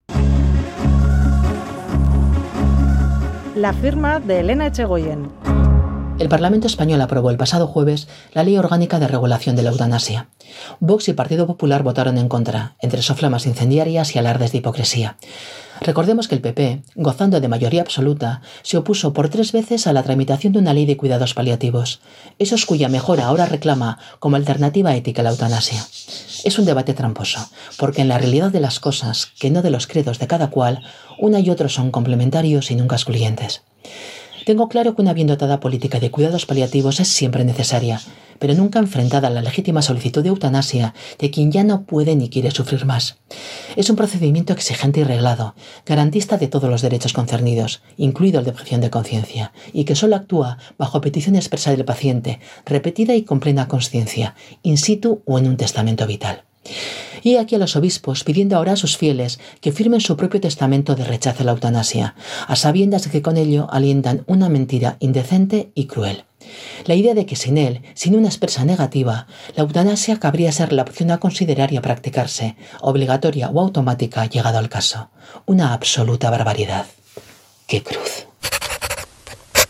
La abogada y ex senadora Elena Etxegoyen analiza y reflexiona sobre un asunto de actualidad de esta jornada
Radio Euskadi LA FIRMA Ley de la eutanasia Publicado: 23/03/2021 08:20 (UTC+1) Última actualización: 23/03/2021 08:20 (UTC+1) Columna radiofónica de opinión en Boulevard de Radio Euskadi Whatsapp Whatsapp twitt telegram Enviar Copiar enlace nahieran